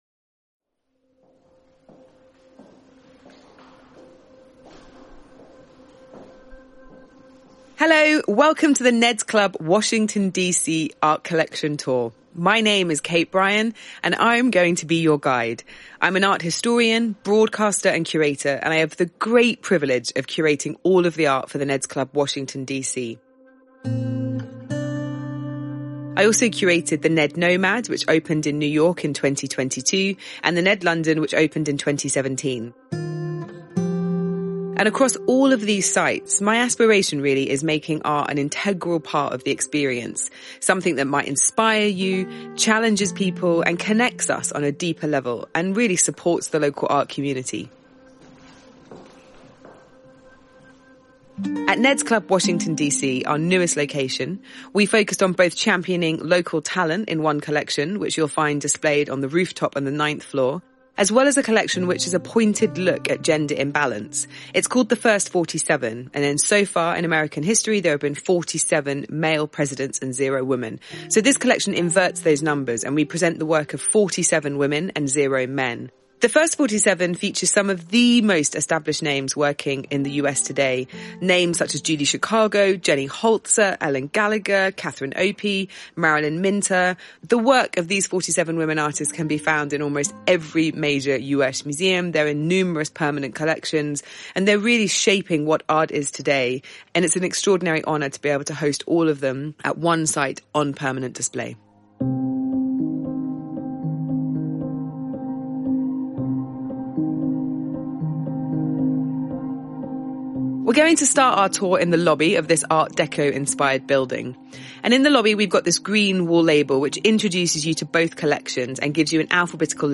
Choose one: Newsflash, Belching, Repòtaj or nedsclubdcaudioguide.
nedsclubdcaudioguide